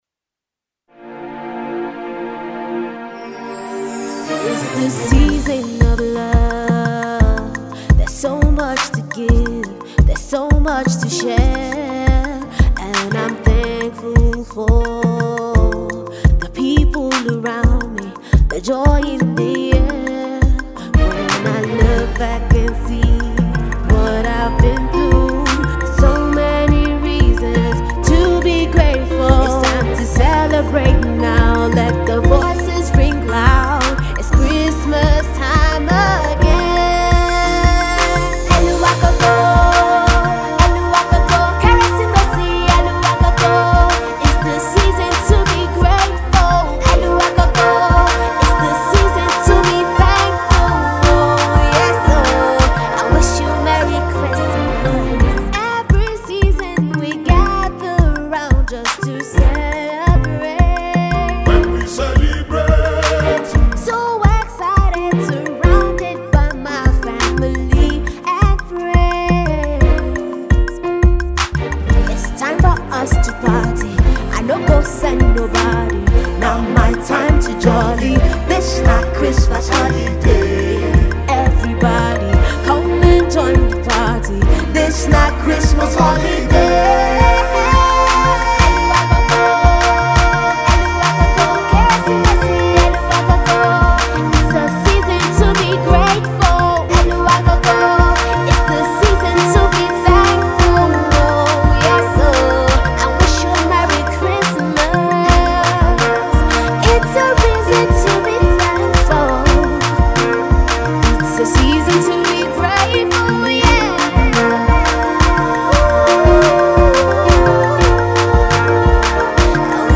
a melodic, authentic and moving tune